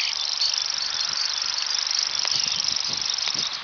Grasshopper Warbler
sing (or rather reel) otherwise with its secretive nature it would be hard to find.
GrasshopperWarbler1.ogg